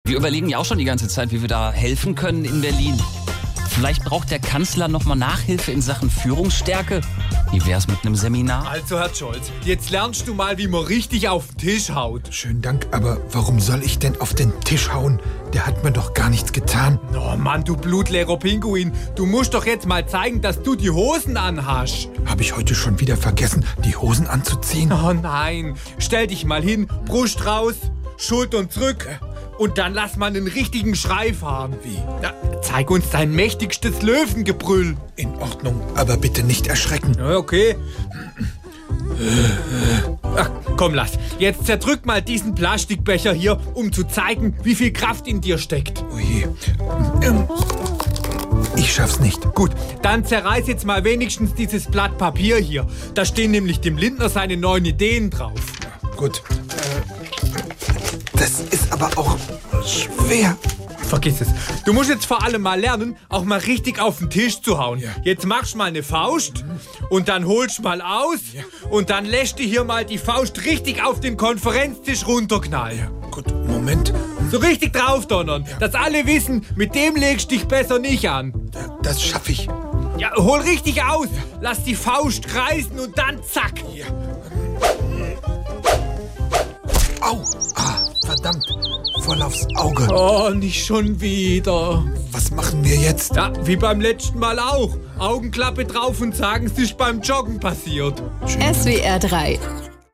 SWR3 Comedy Scholz lernt auf den Tisch zu hauen